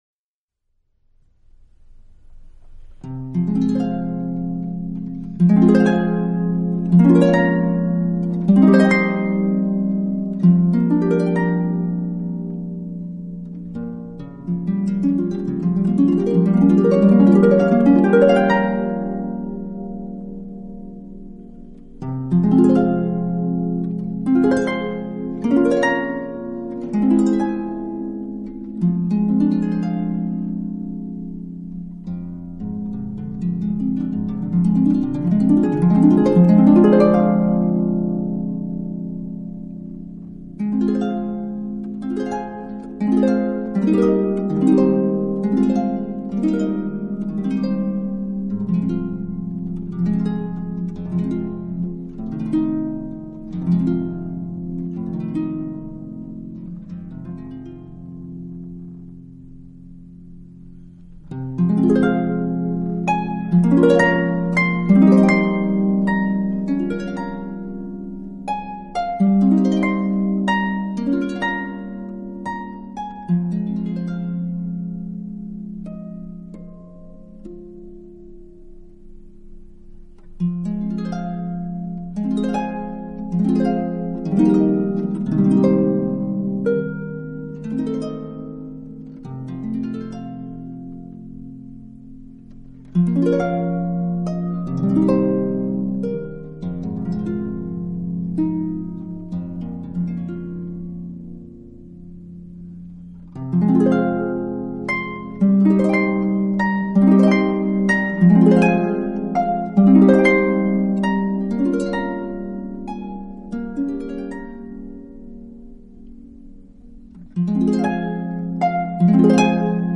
竖琴专辑
竖琴空冷的音色极易暴露出编曲配器的苍白无力，似乎很难找到合适的伴奏
晶莹如潺潺流水的琴声，静